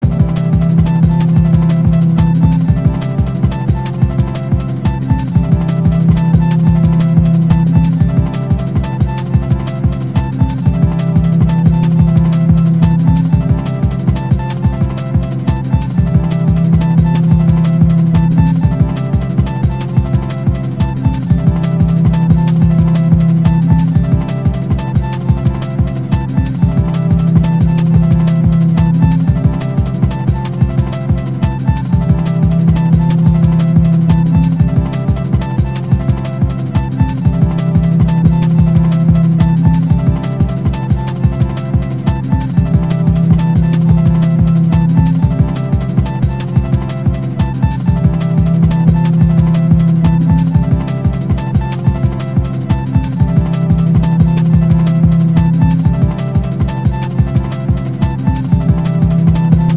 mp3（59sec mono 466kb）　new!
D&Bのつもりで作ったんだけど、ベースが入ってません（笑）。
オルガンがお約束な感じ。